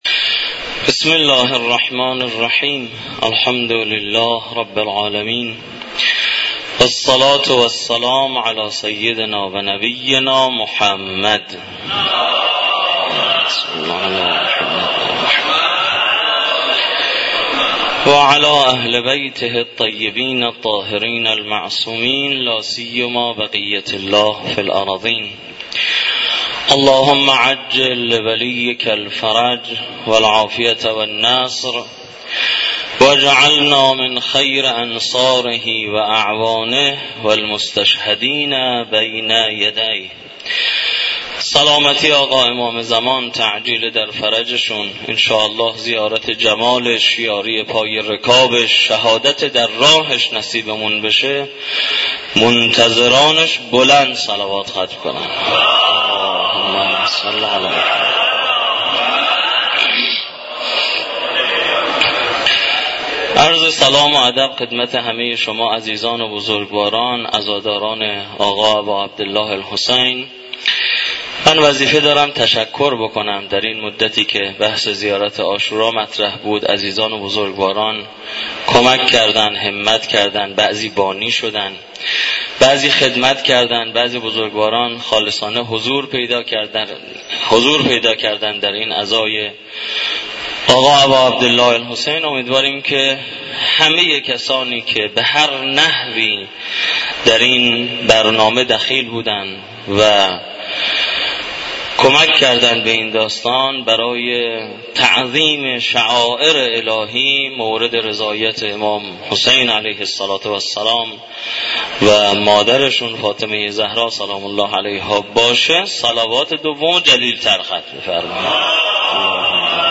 مراسم عزاداری حضرت اباعبدالله الحسین علیه السلام همراه با قرائت زیارت عاشورا ، سخنرانی و مدّاحی در دانشگاه کاشان برگزار شد.